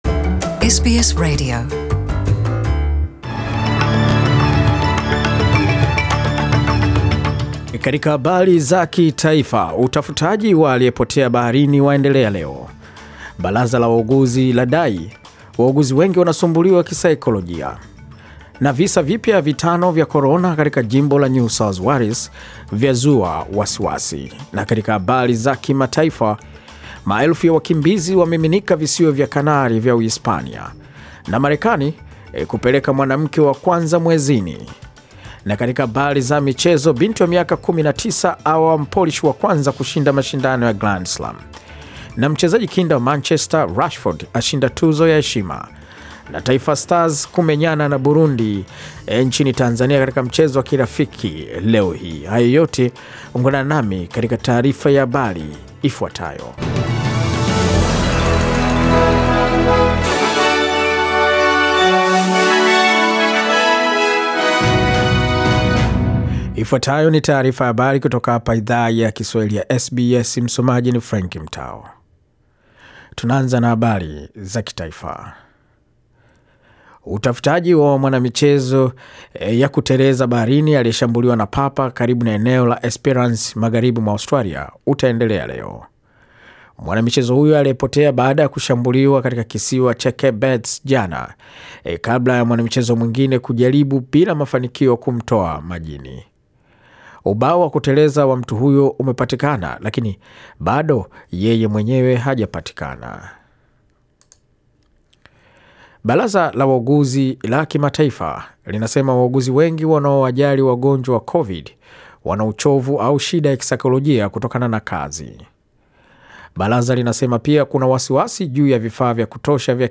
Swahili News October 10